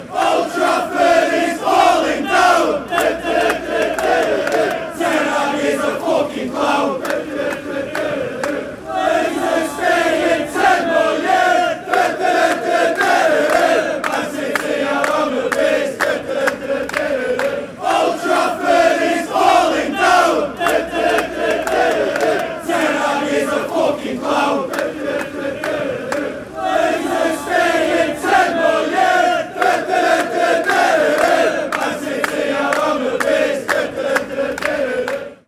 Old Trafford is Falling Down Chant